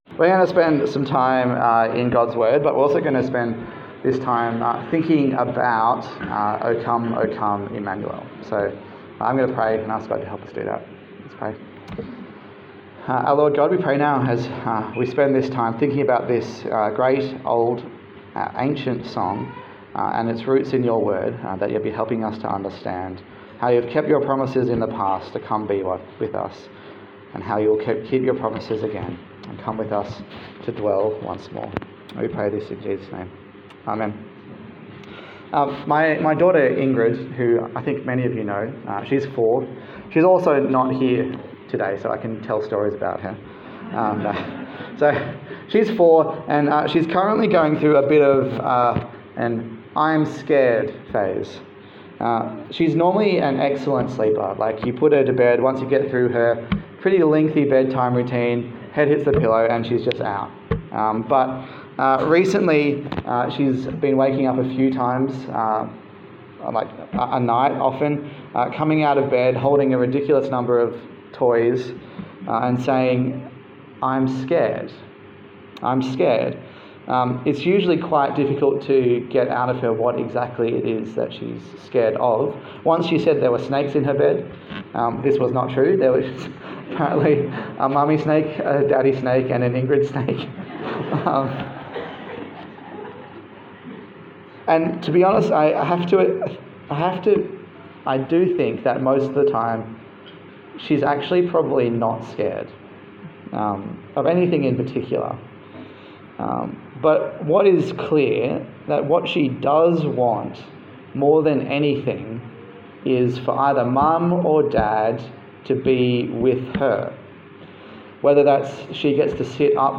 A sermon in the Advent series 2024 on Carols
Service Type: Sunday Service